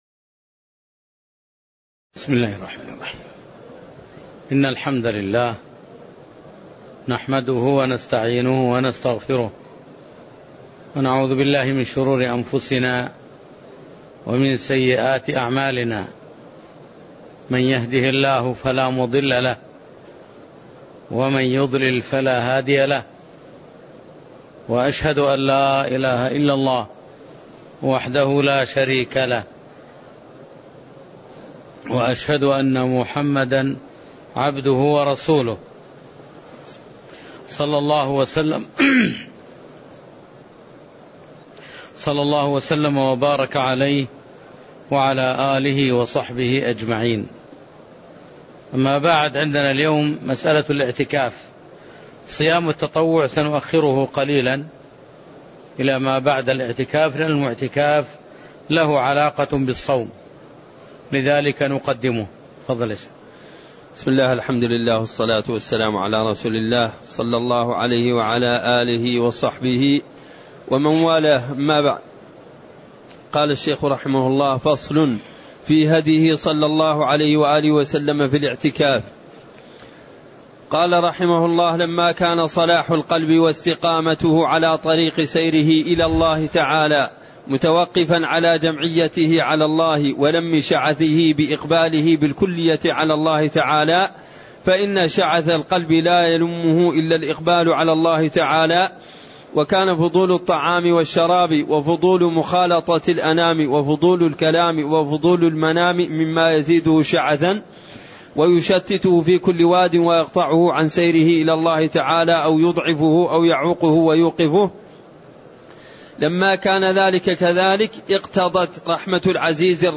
تاريخ النشر ١٧ رمضان ١٤٣٠ هـ المكان: المسجد النبوي الشيخ